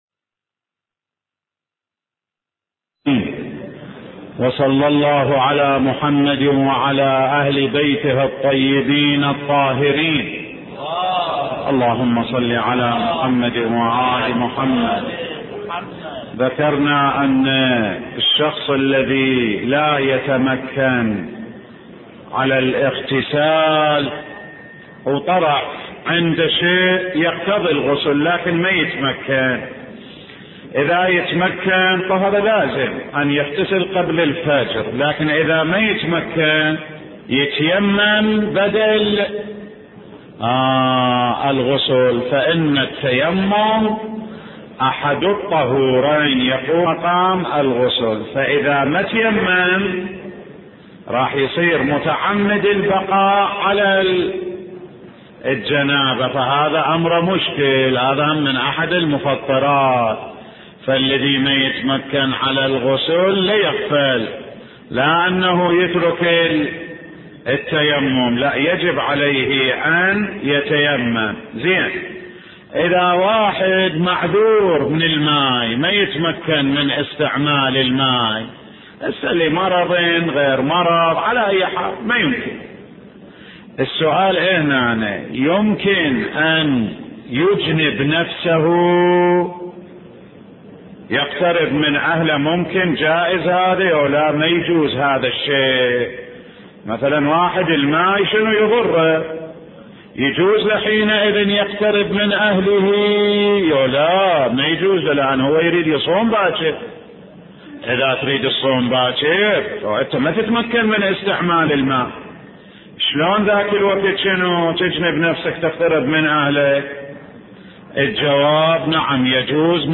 سلسلة دروس في نهج البلاغة (19) – الخطبة 192 (القاصعة) من نهج البلاغة